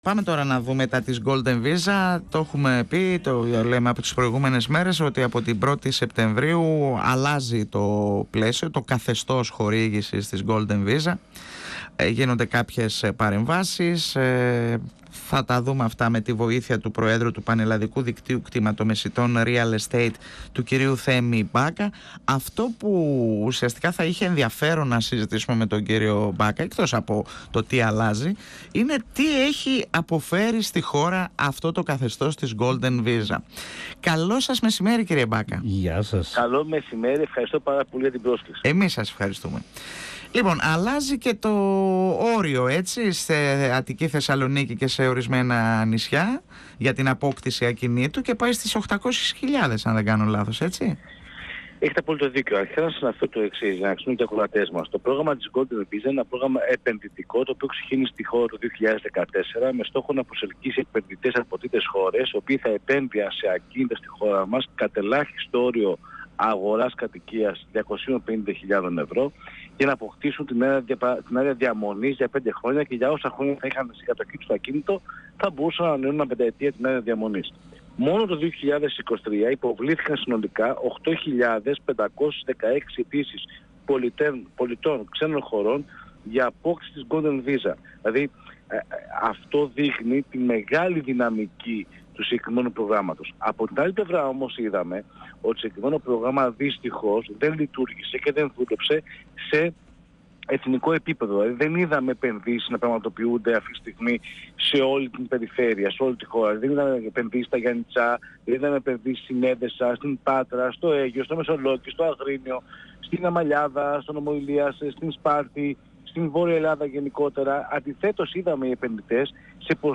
μιλώντας στην εκπομπή «Εδώ και Τώρα» του 102FM της ΕΡΤ3.